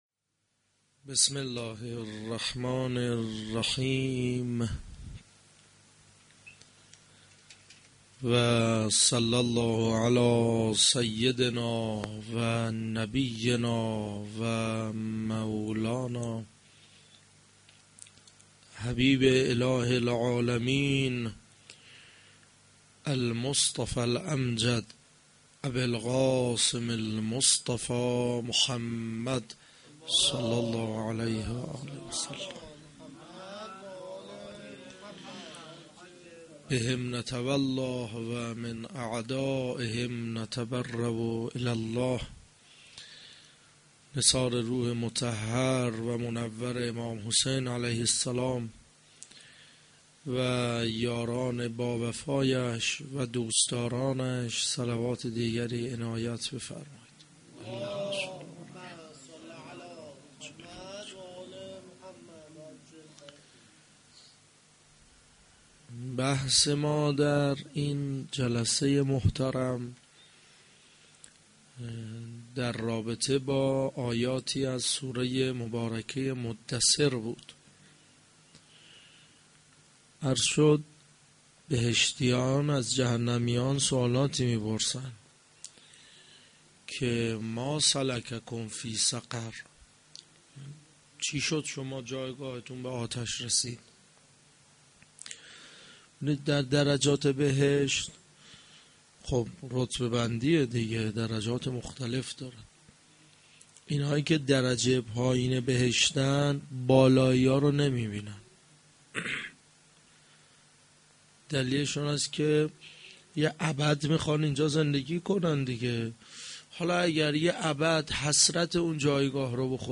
mo92-sh4-Sokhanrani.mp3